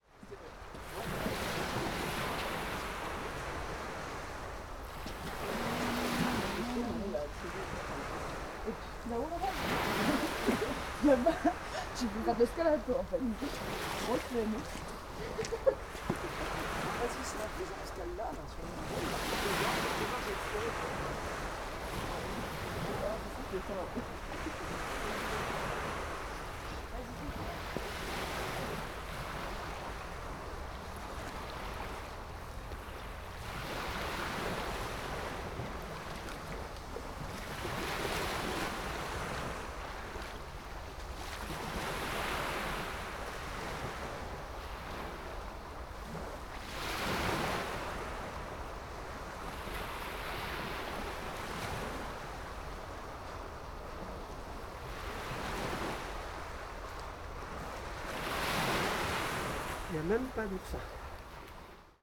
Et on a débouché sur une petite crique ravissante, de sable, avec un joli son de clapot, très doux.
Du coup j’ai posé l’enregistreur sur mes fringues, micros intégrés, roule ma poule, avant de me mettre à l’eau.
Pendant que je barbotais deux jeunes femmes sont venues voir la mer, en bavardant et riant.
Marseille, 29/12/2023, 17h30